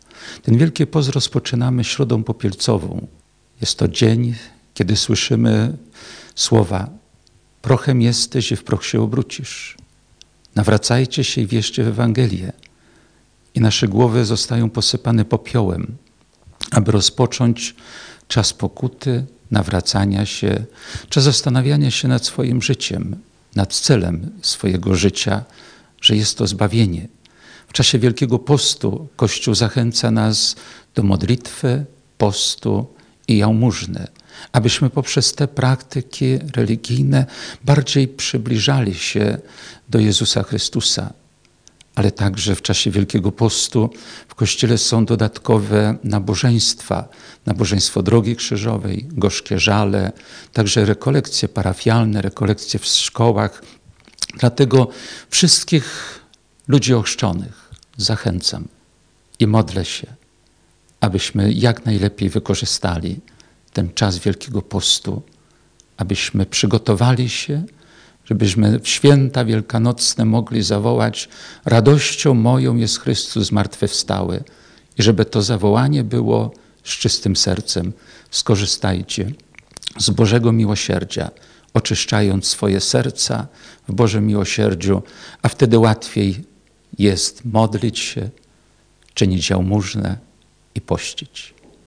– Czas Wielkiego Postu – to okres przygotowania się do Paschy Zmartwychwstania Jezusa Chrystusa, do zwycięstwa Chrystusa nad śmiercią – mówi ks. Jerzy Mazur Biskup Diecezji Ełckiej.
biskup-środa-popielcowa.mp3